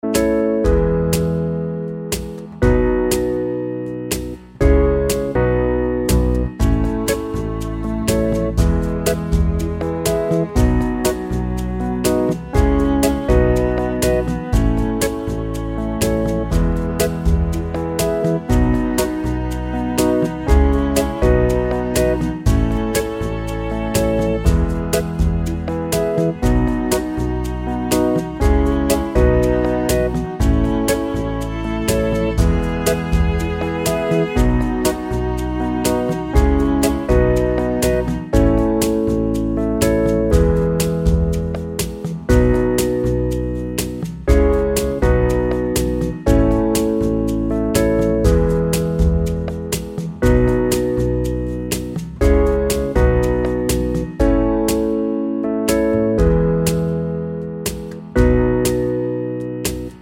Original Key